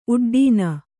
♪ uḍḍīna